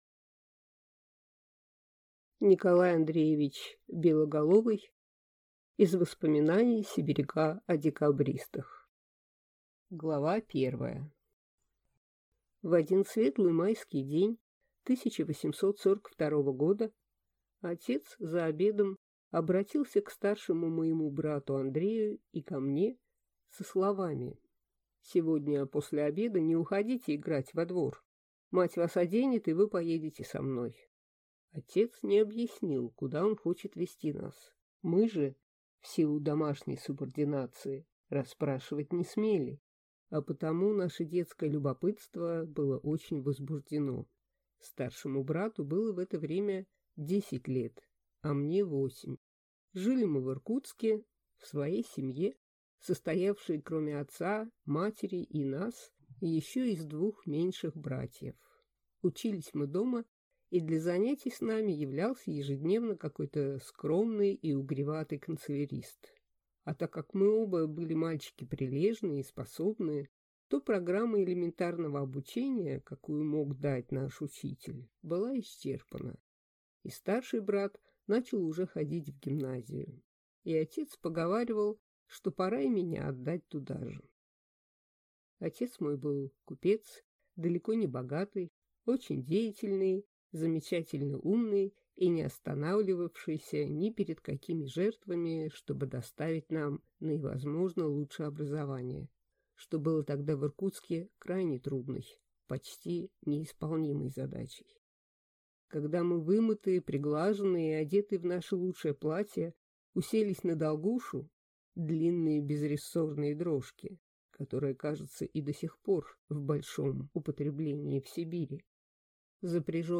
Аудиокнига Из воспоминаний сибиряка о декабристах | Библиотека аудиокниг